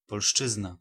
Ääntäminen
Ääntäminen Tuntematon aksentti: IPA: [pɔlˈʂt͡ʂɨzna] Haettu sana löytyi näillä lähdekielillä: puola Käännös Ääninäyte Erisnimet 1.